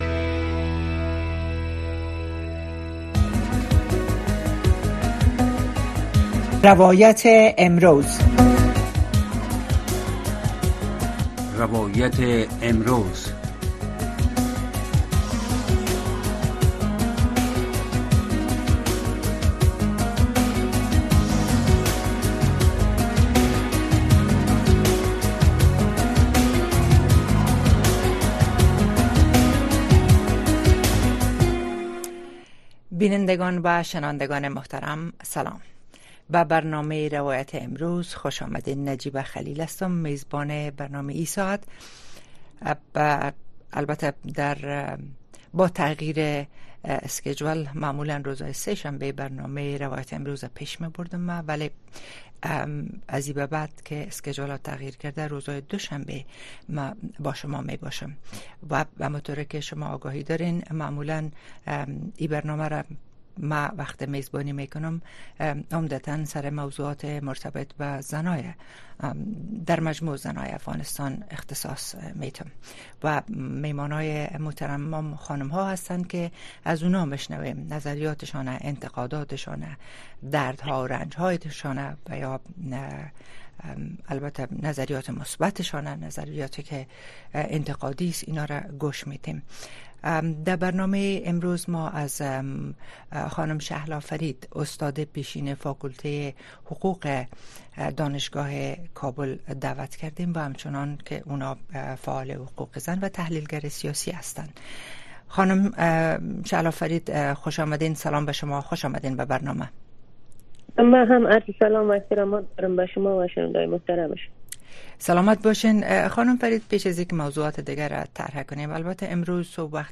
در برنامۀ روایت امروز شرح وضعیت در افغانستان را از زبان شرکت کنندگان این برنامه می‌شنوید. این برنامه شب‌های یک‌شنبه، دوشنبه، سه‌شنبه و پنج‌شنبه از ساعت ٩:۰۰ تا ۹:۳۰ شب به گونۀ زنده صدای شما را در رادیو و شبکه‌های ماهواره‌ای و دیجیتلی صدای امریکا پخش می‌کند.